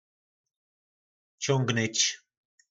Slovník nářečí Po našimu